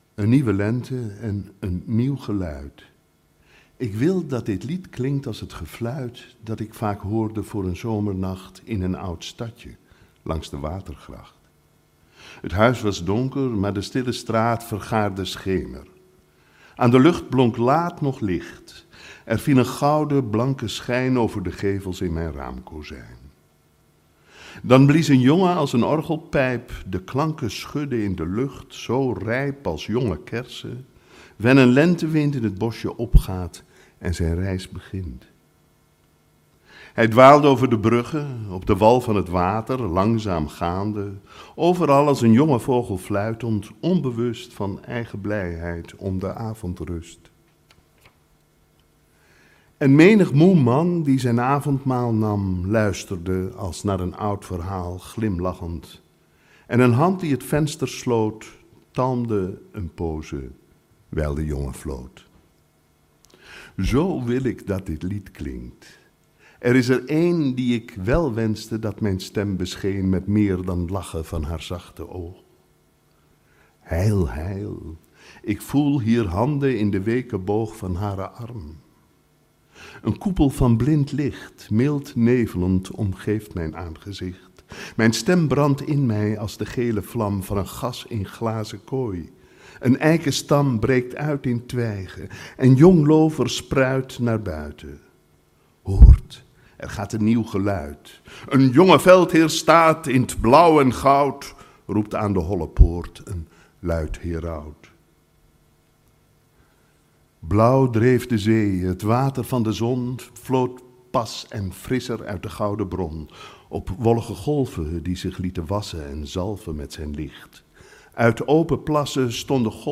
Voor de nieuwe liefde droeg hij ooit de eerste 20 minuten voor en dat smaakt naar meer!